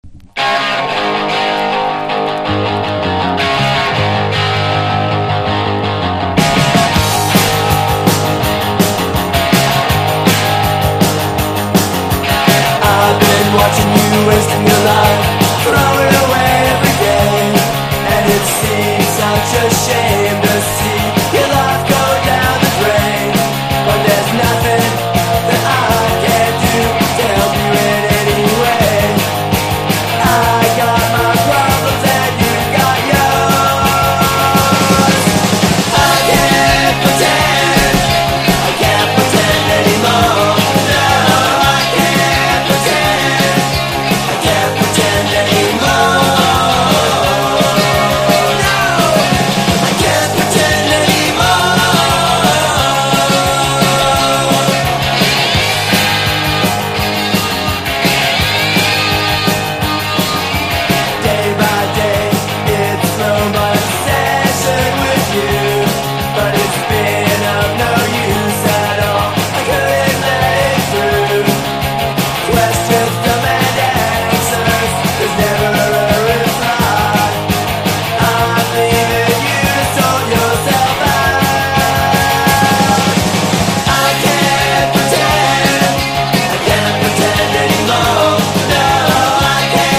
初期PUNK / POWER POP